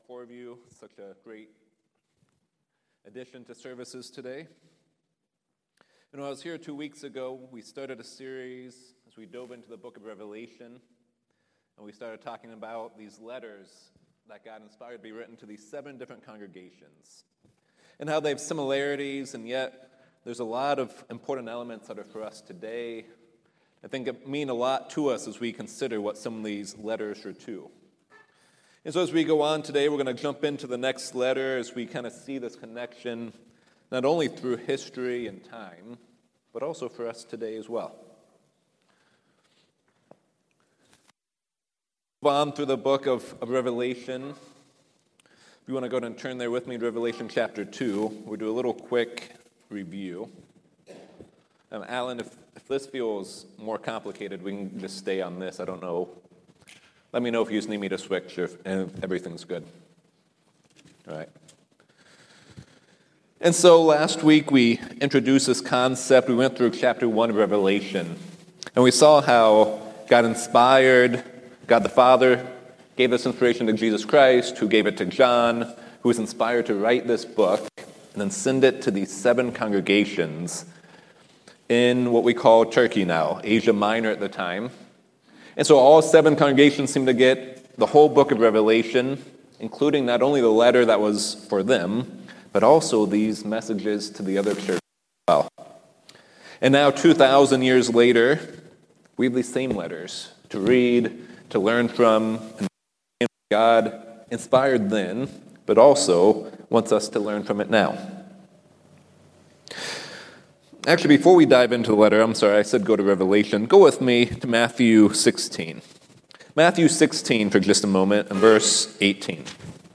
In part two of the sermon series on the seven , we dive into the letter written to the Smyrna. In this letter, the church is told to stay faithful until death and you will receive the crown of life.